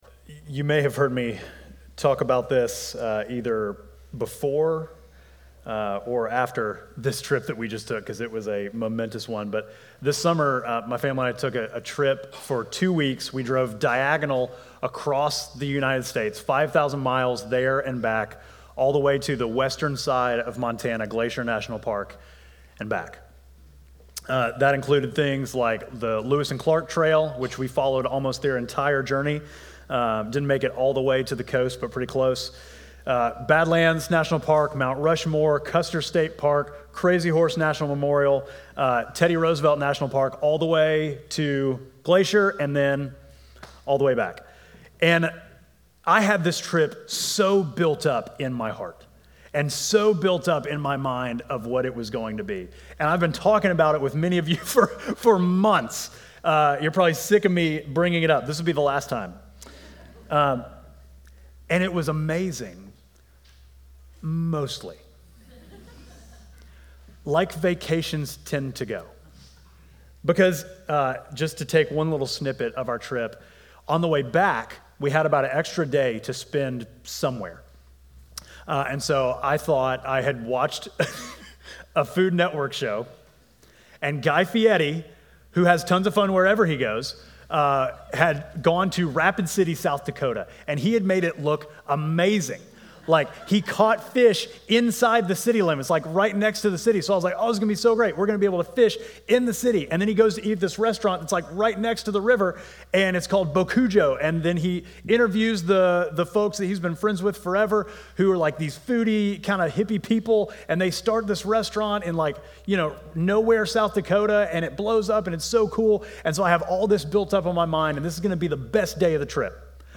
Midtown Fellowship Crieve Hall Sermons The Journey Home Aug 11 2024 | 00:31:41 1x 00:00 / 00:31:41 Subscribe Share Apple Podcasts Spotify Overcast RSS Feed Share Link Embed